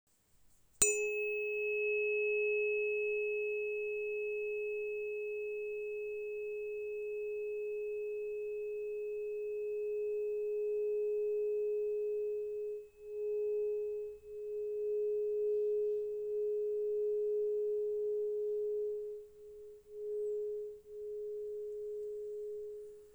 Therapeutic Tuning Fork 418,3 Hz Bones Aluminum CZ
The gentle but firm tone of this frequency helps to strengthen body awareness, promote regeneration, and restore a sense of security and grounding.
• Material: aluminum
• Frequency: 418.3 Hz (A♭)
• A pure, long reverberation for deep relaxation and harmonization.